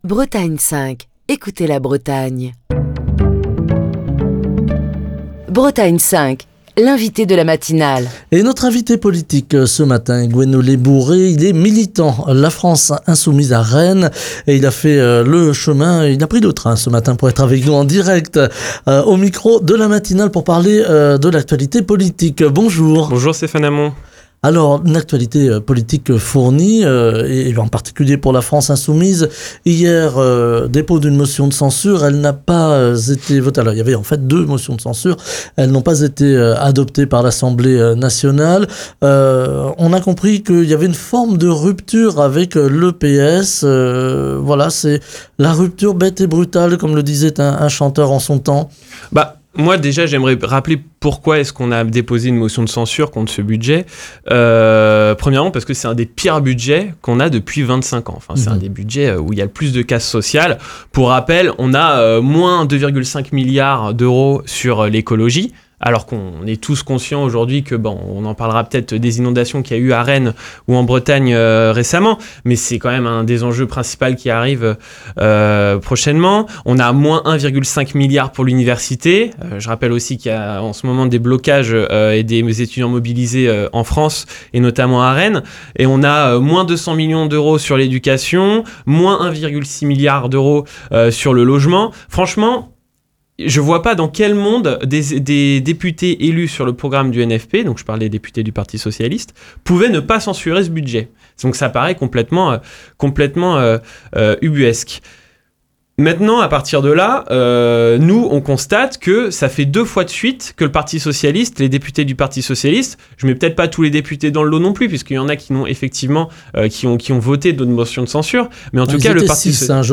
Émission du 6 février 2025.